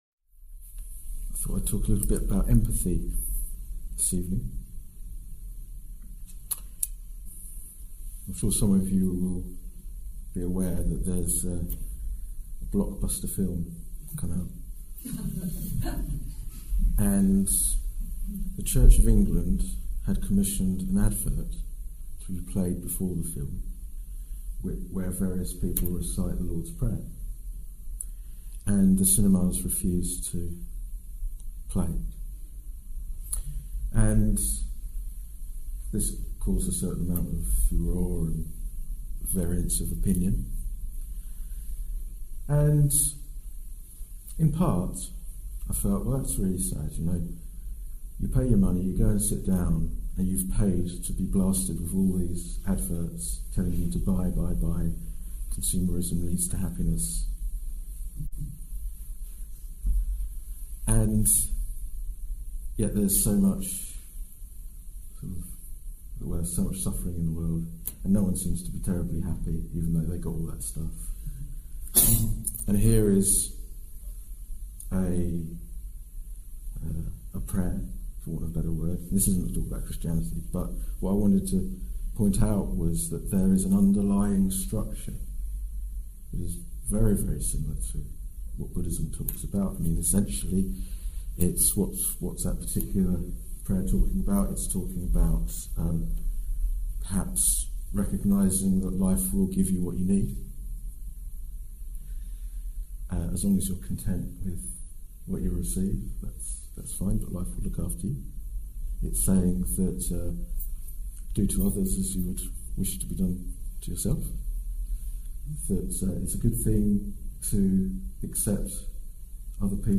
This talk was given in December 2015